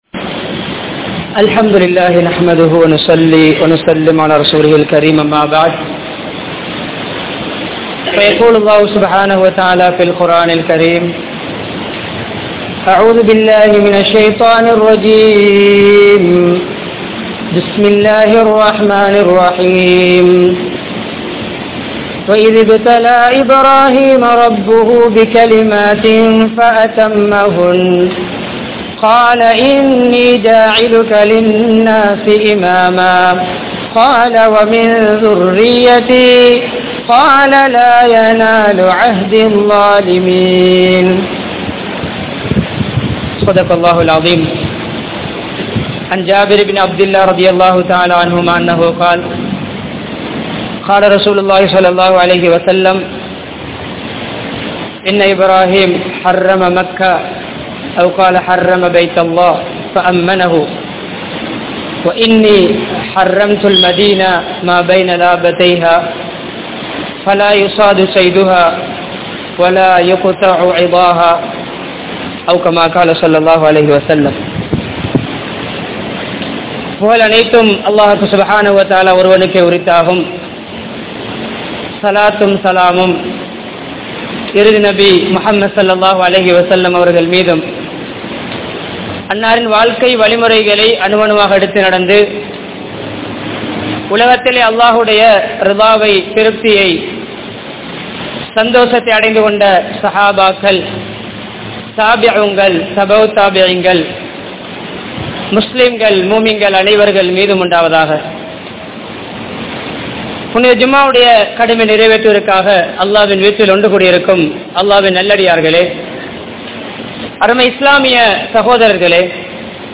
Ibrahim(Alai)Avarhalin Mun Maathirihal (இப்றாஹீம் அலை அவர்களின் முன்மாதிரிகள்) | Audio Bayans | All Ceylon Muslim Youth Community | Addalaichenai
Watthala, Peliyagoda Jumua Masjidh